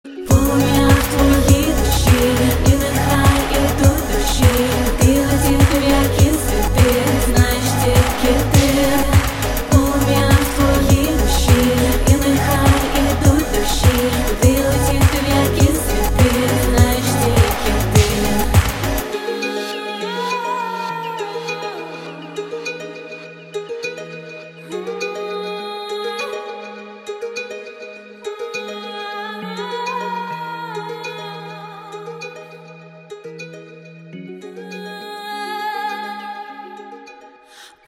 • Качество: 128, Stereo
поп
спокойные